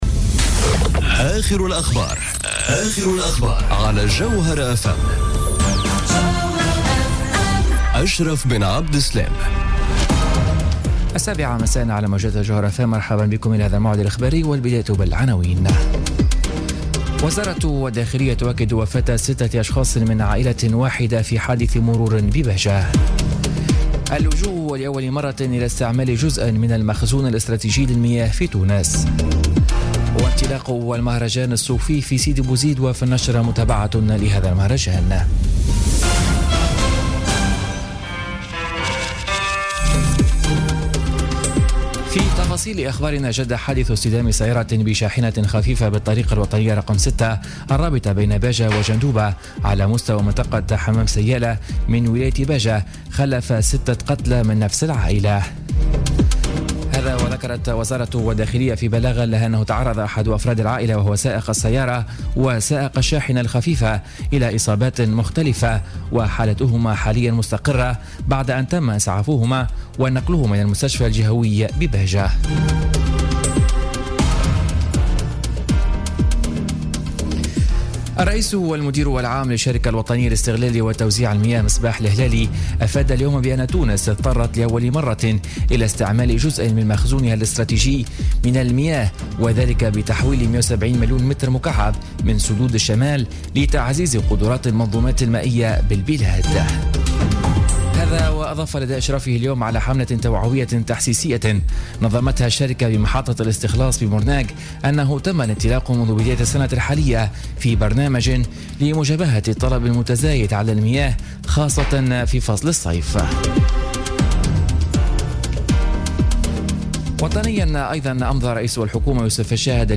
نشرة أخبار السابعة مساء ليوم السبت 1 جويلية 2017